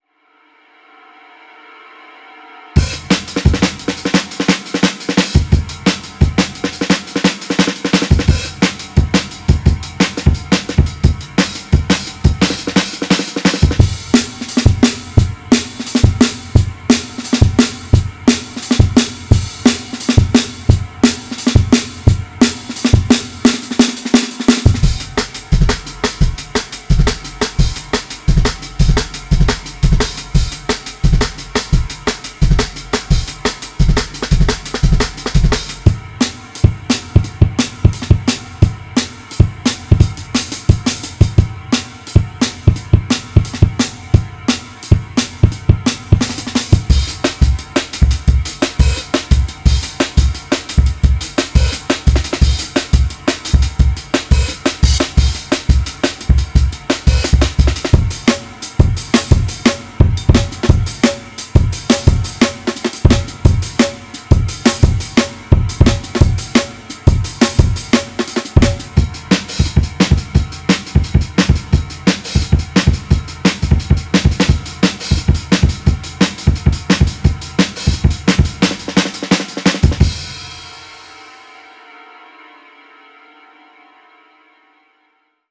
TUNED D’n’B & Jungle Drums